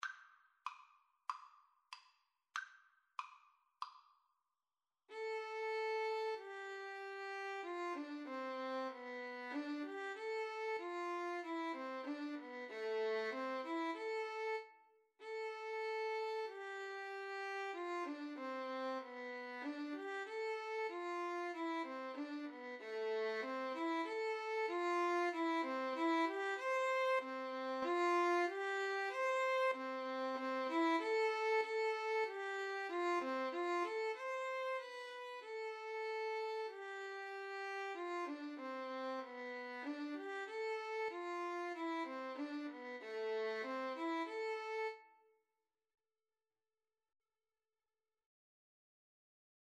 C major (Sounding Pitch) (View more C major Music for Violin-Flute Duet )
= 95 Moderato
4/4 (View more 4/4 Music)
Violin-Flute Duet  (View more Easy Violin-Flute Duet Music)